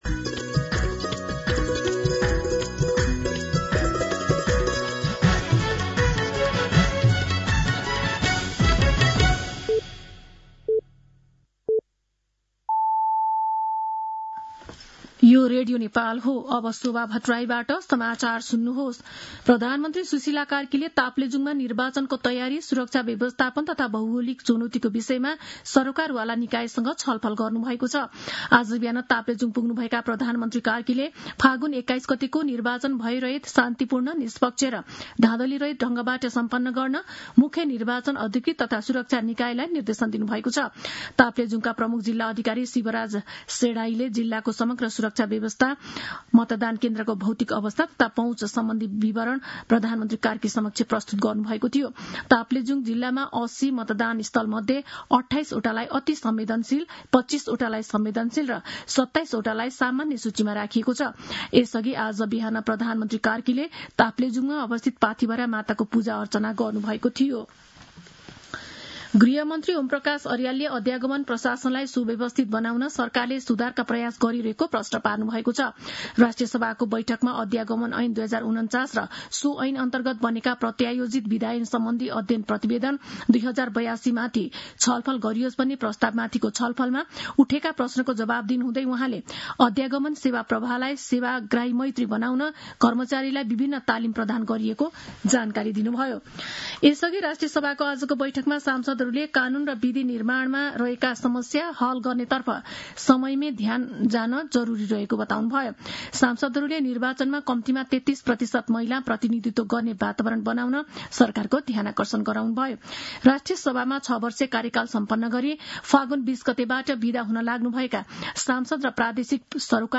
दिउँसो ४ बजेको नेपाली समाचार : १ फागुन , २०८२